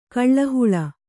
♪ kaḷḷa huḷa